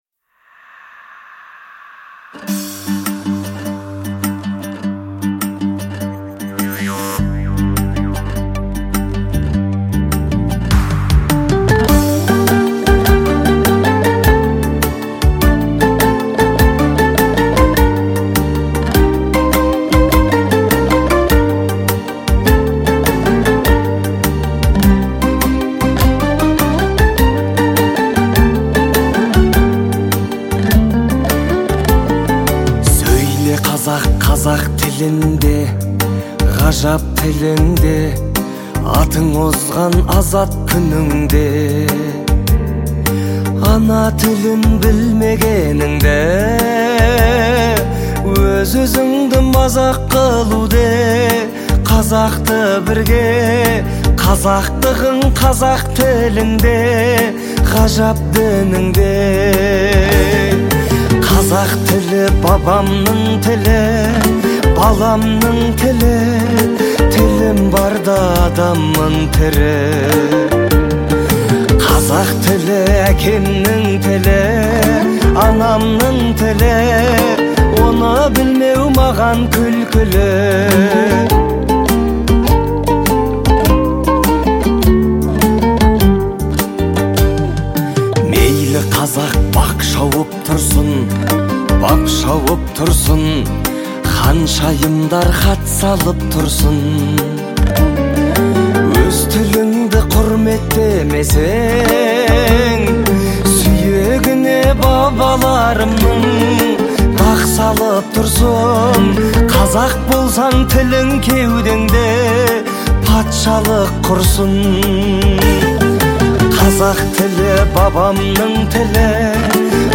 это яркое произведение в жанре поп
сочетая современное звучание с традиционными элементами.